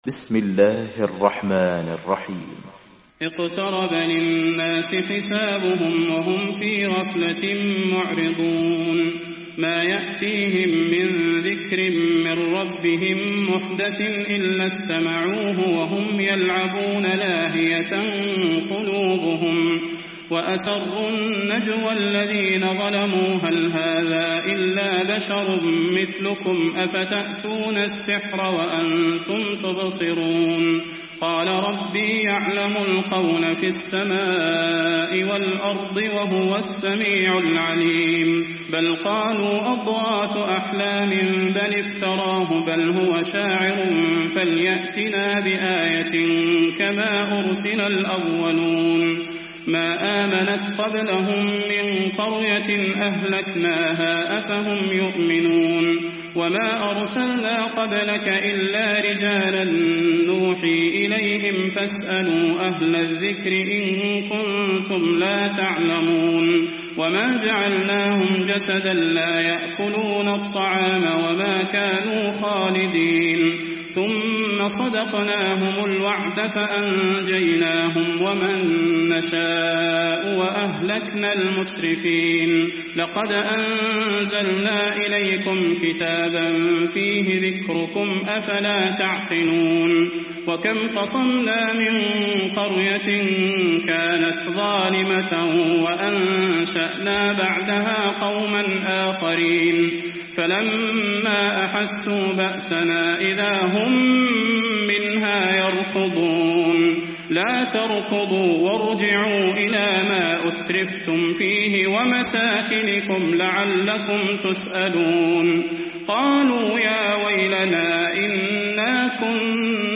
تحميل سورة الأنبياء mp3 بصوت صلاح البدير برواية حفص عن عاصم, تحميل استماع القرآن الكريم على الجوال mp3 كاملا بروابط مباشرة وسريعة